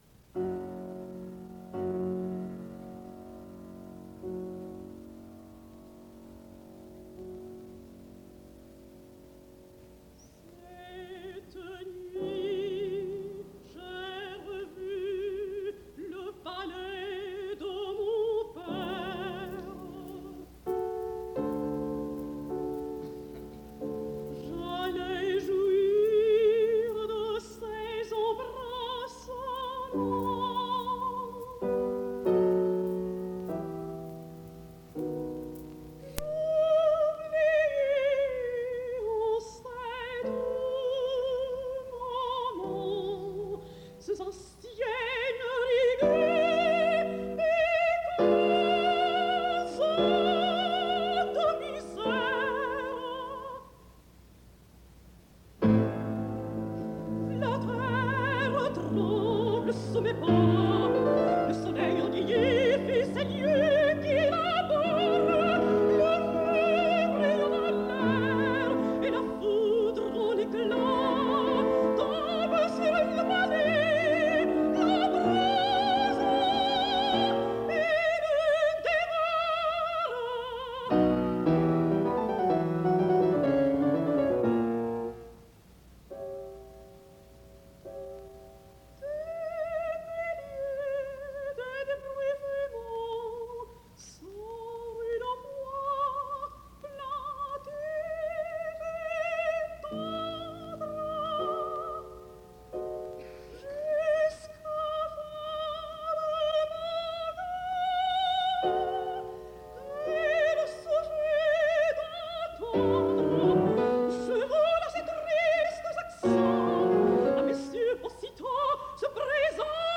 al piano